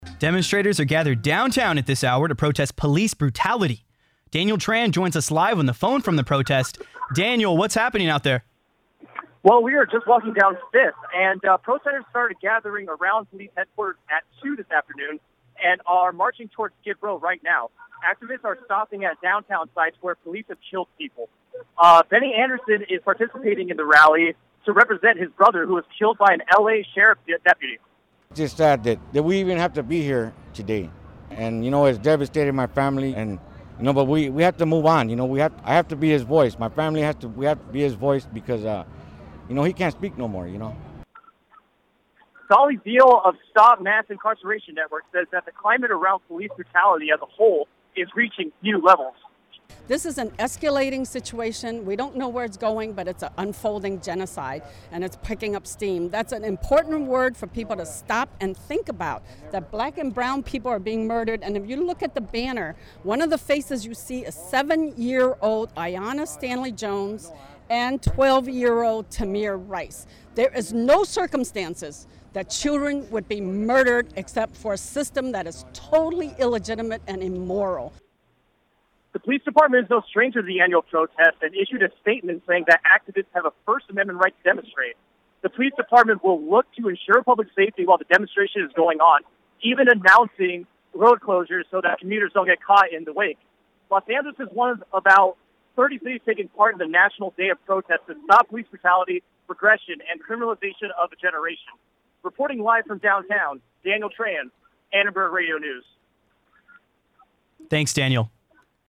Live from the march against police brutality in downtown